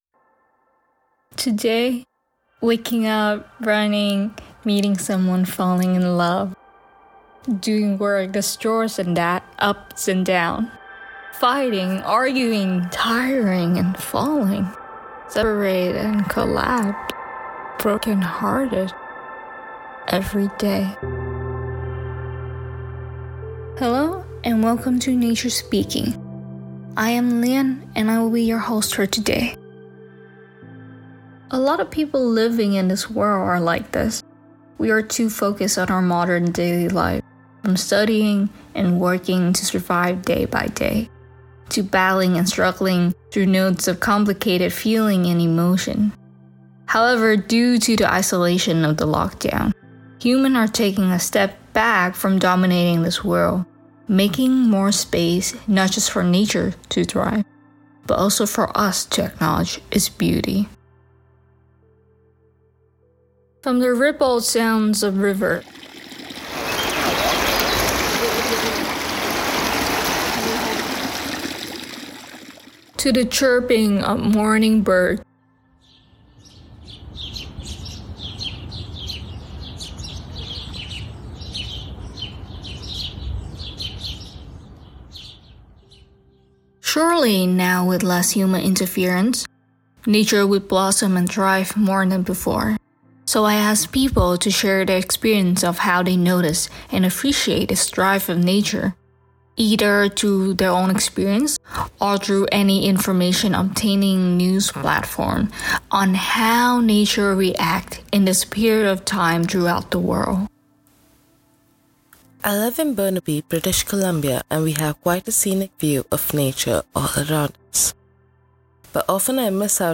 Audio non-musical